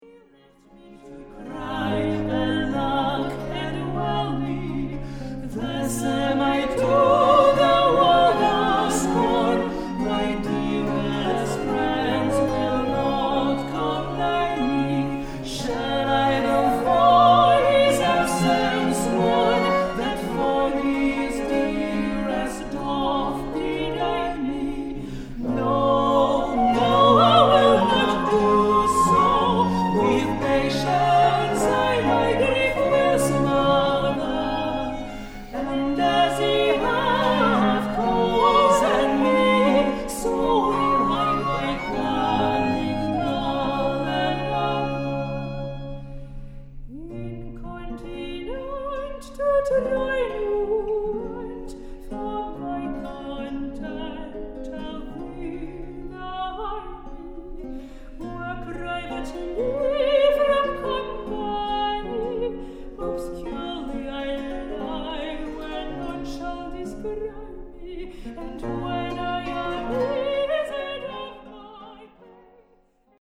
flûte traversière, smallpipes
mezzo-soprano
Baryton, percussion, tympanon
violon
harpe triple
viole de gambe
archiluth, cistre
ténor